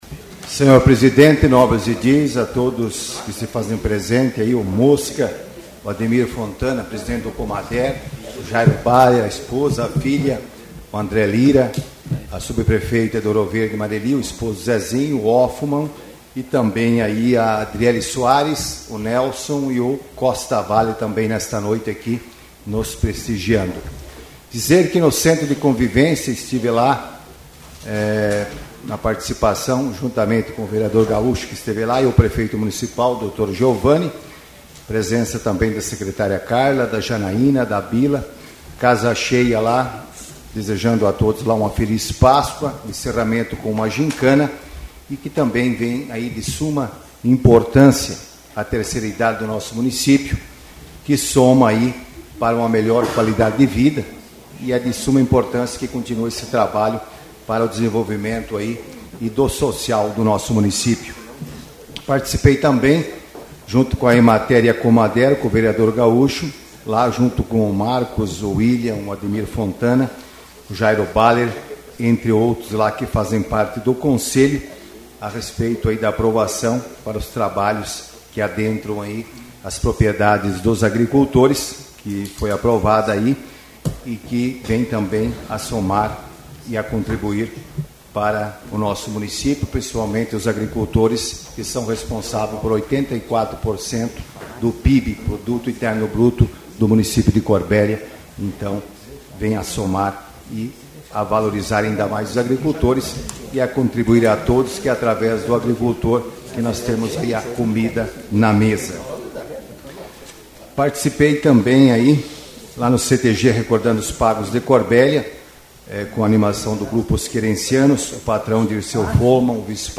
8ª Sessão Ordinária